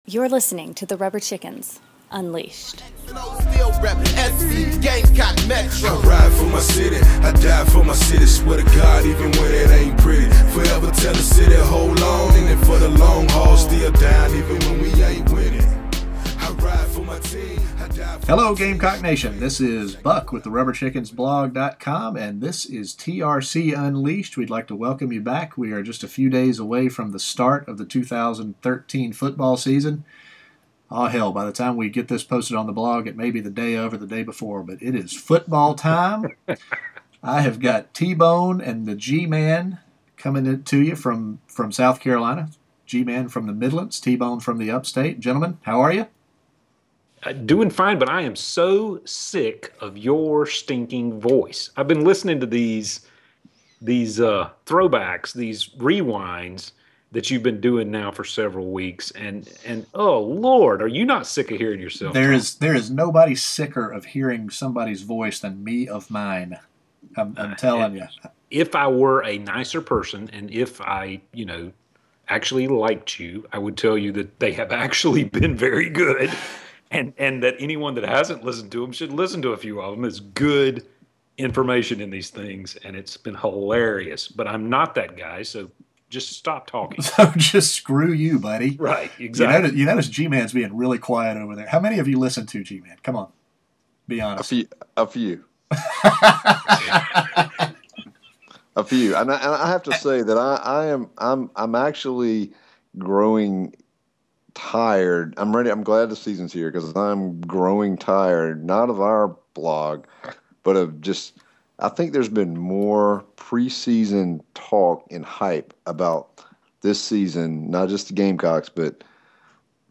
(Interview begins at 17:15.)
On either side of the interview the TRC gang is a bit more punch drunk than usual, trying to figure out why we are so stinking worried about this game on Thursday.